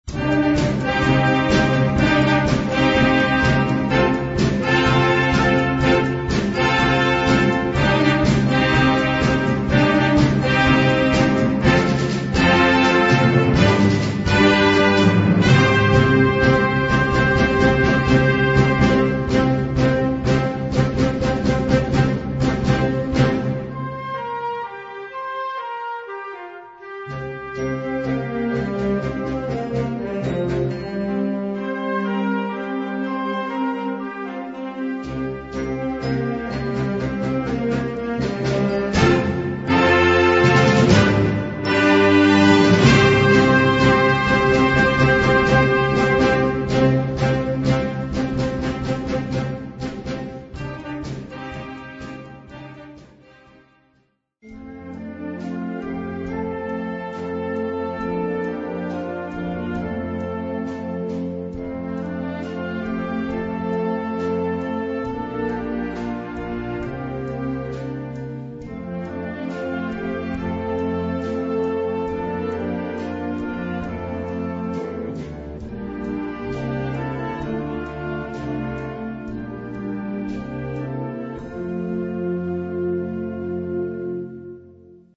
Kategorie Blasorchester/HaFaBra
Unterkategorie Suite